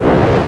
flamer sounds
fl_fire.ogg